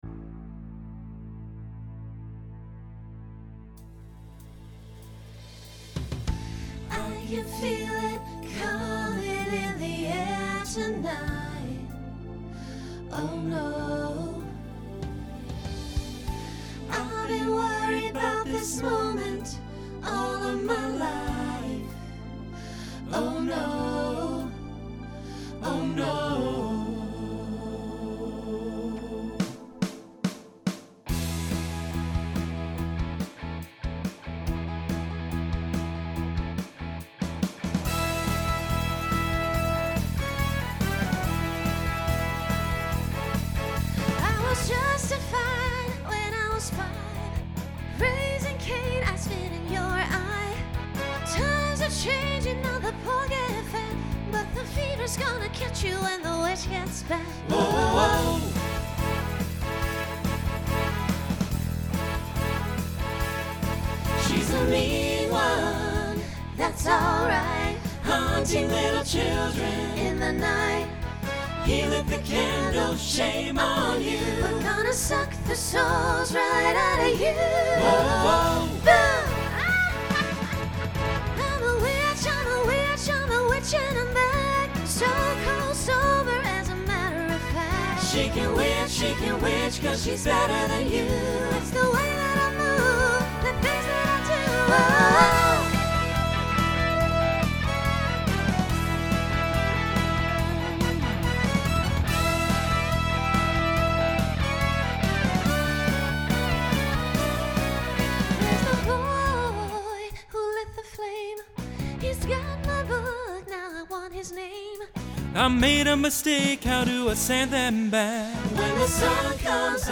Genre Rock Instrumental combo
Story/Theme Voicing SATB